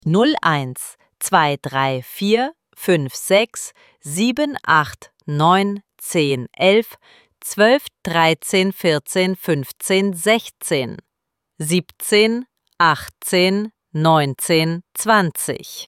IZGOVOR – BROJEVI 1-20:
ElevenLabs_Text_to_Speech_audio-47.mp3